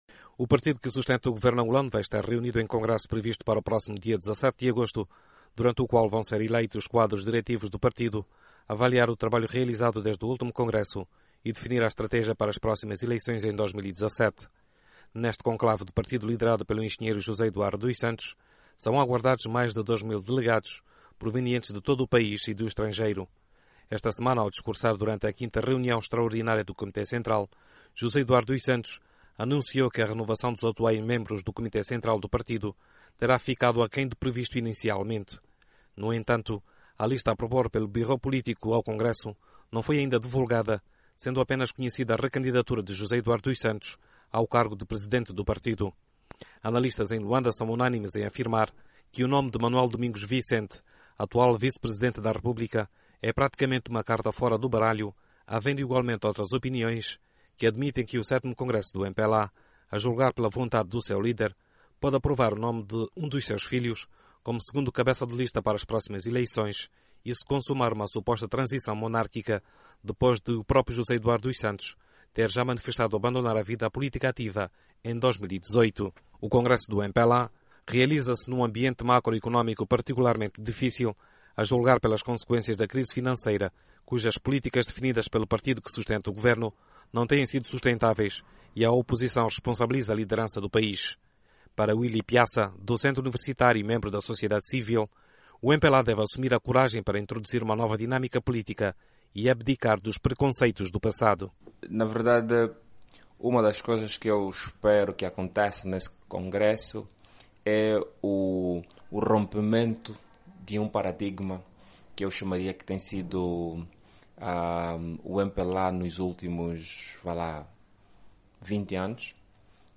Congresso do MPLA em debate - 20:05